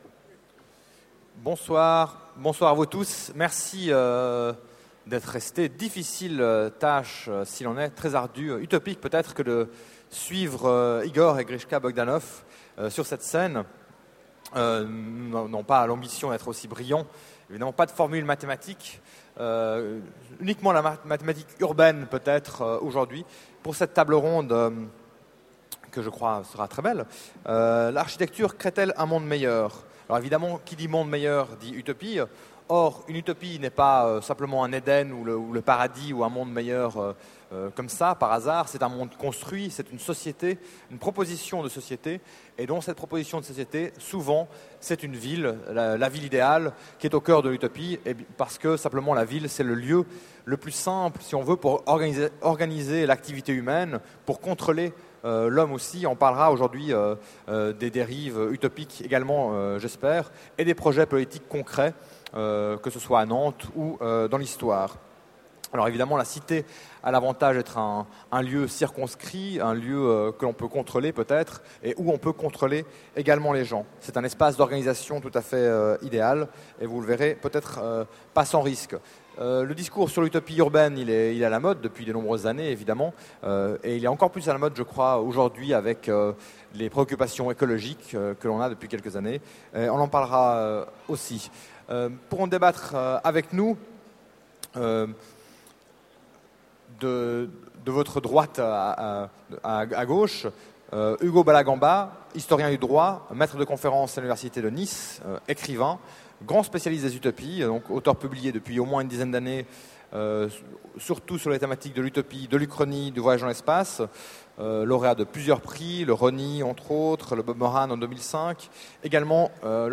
Voici l'enregistrement de la conférence L'architecture crée-t-elle un monde meilleur ? aux Utopiales 2009.